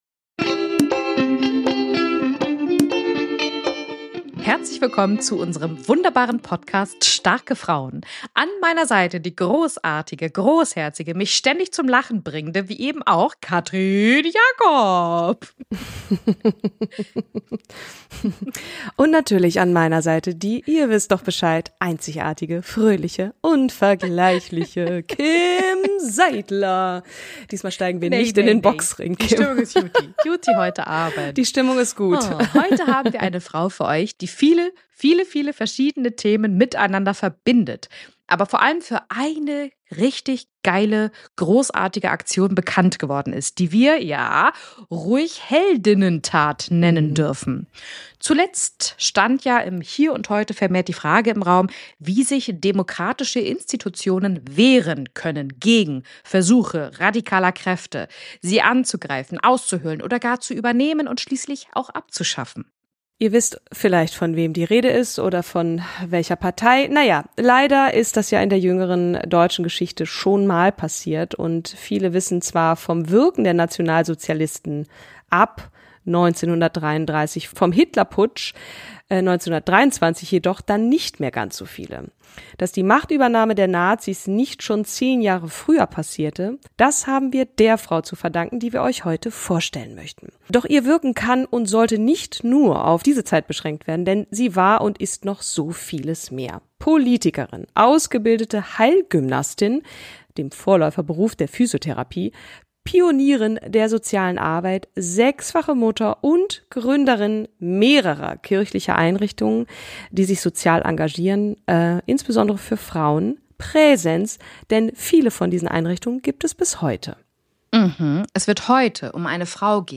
Täglich zeigt sie ihren Alltag und echte Trainingseinblicke mit ihren Pferden Sam, Blue und Disney. Im Interview erzählt sie unter anderem, weshalb sie sich eigentlich nicht als …